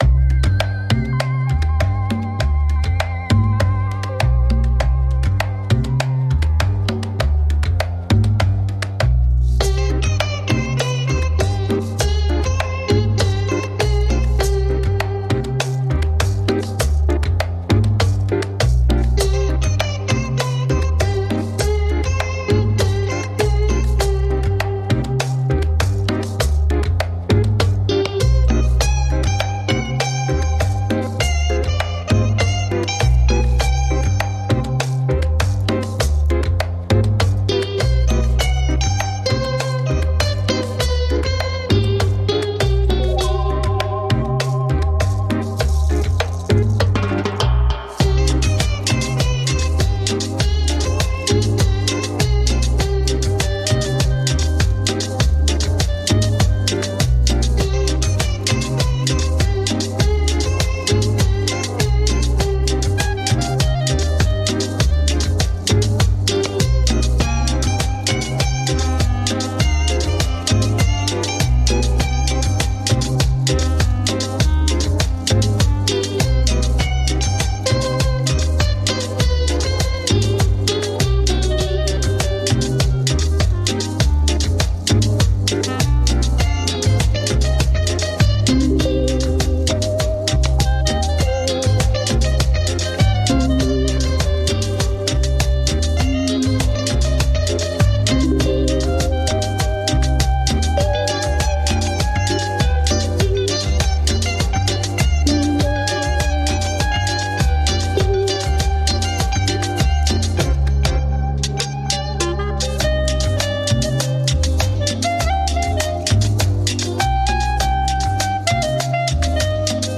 Tags: Cumbia , Tropical
futuristic electronic cumbia
baritone and clarinet